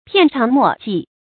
片长末技 piàn cháng mò jì
片长末技发音
成语注音 ㄆㄧㄢˋ ㄔㄤˊ ㄇㄛˋ ㄐㄧˋ